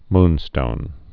(mnstōn)